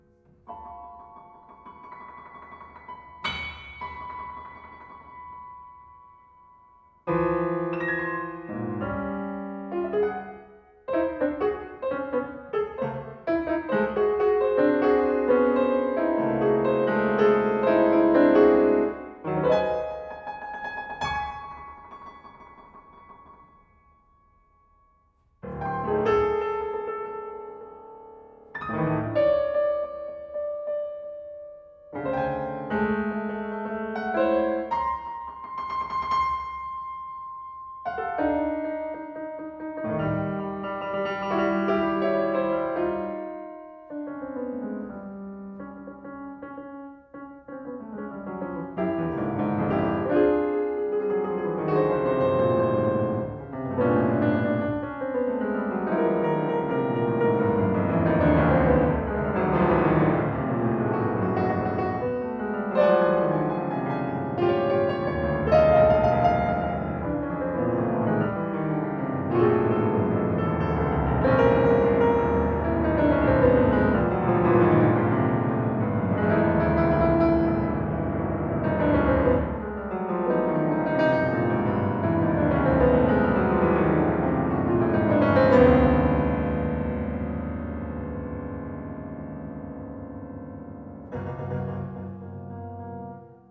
Using military shortwave radio broadcasts as a guide, I devise a musical language and structure which explores superimposition, periodicity and aperiodicity, unity and fragmentation.
The piece consists of 3 movements performed without pause:
Pianist
Recorded: Emmaus-Ölberg Kirchen Berlin Kreuzberg 17/3/2022